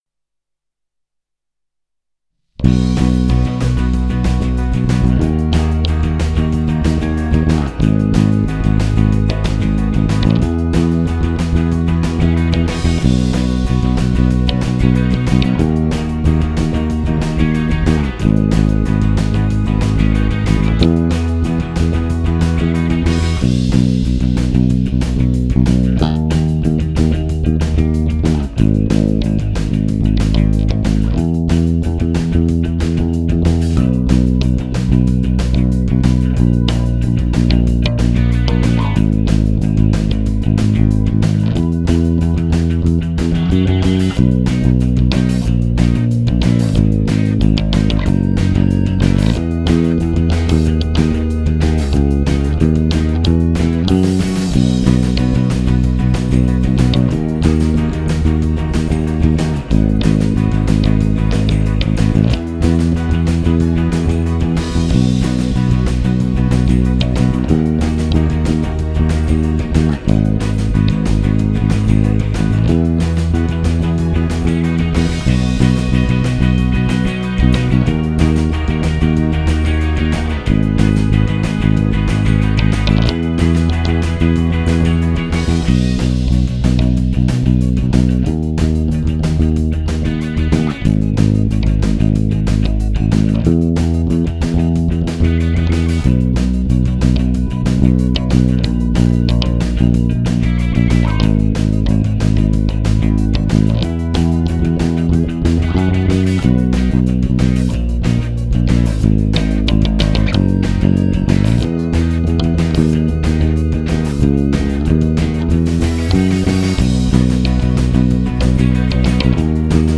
instrumentale
Cover / Basse uniquement